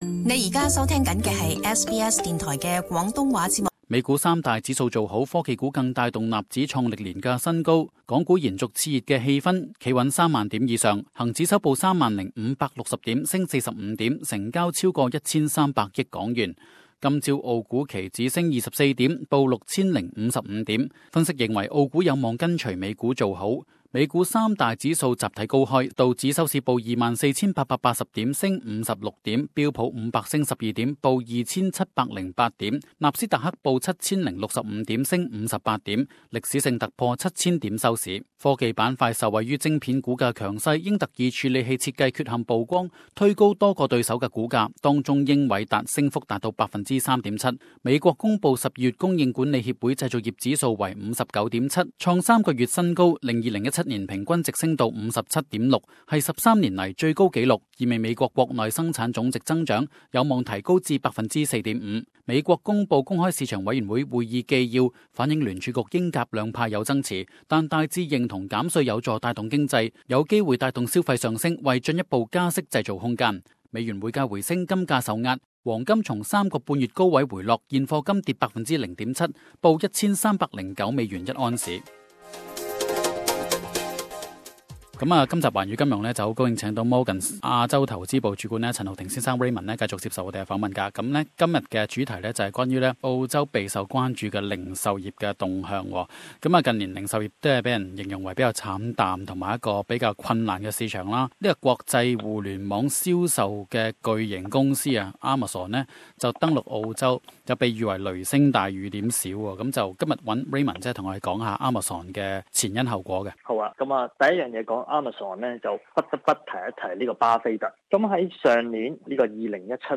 分析指，澳洲零售業面對結構性問題嚴重，即使阿馬遜未有進軍，本地零售商同樣需要作出改革。點擊收聽訪問。